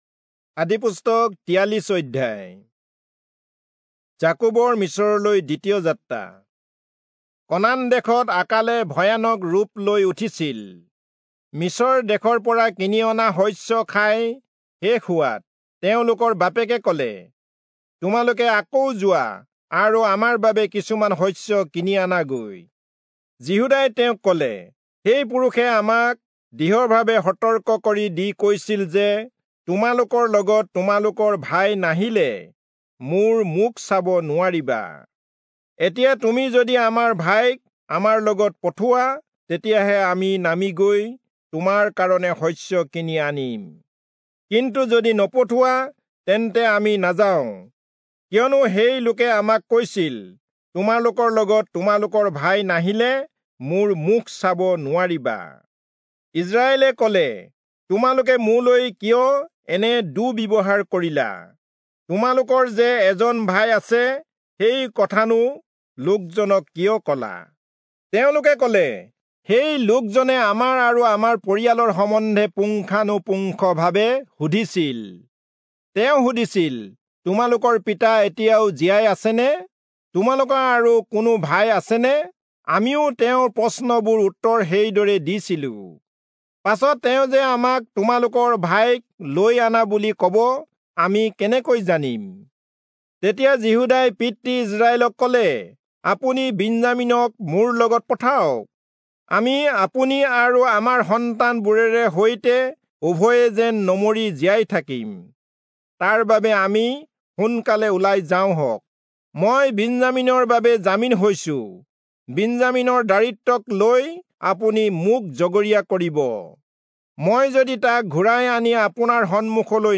Assamese Audio Bible - Genesis 33 in Alep bible version